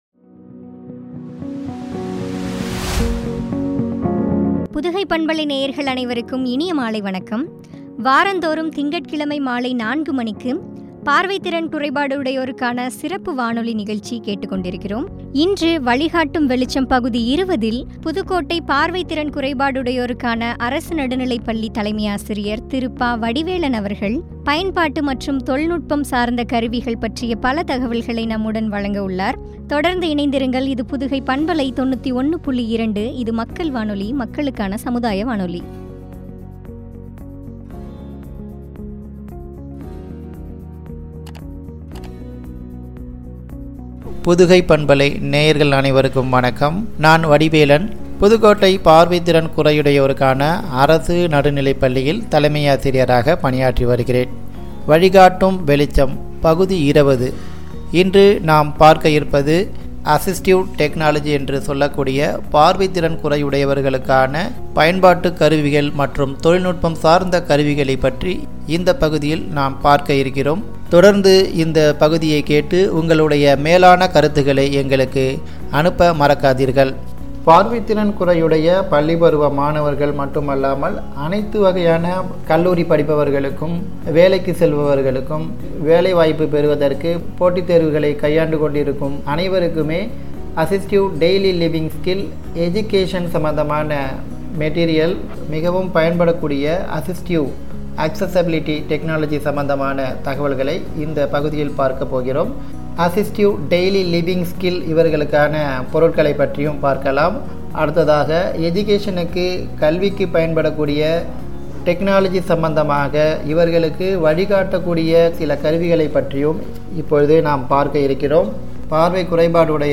பார்வை திறன் குறையுடையோருக்கான சிறப்பு வானொலி நிகழ்ச்சி
” (பயன்பாட்டு மற்றும் தொழில்நுட்பக் கருவிகள்), குறித்து வழங்கிய உரையாடல்.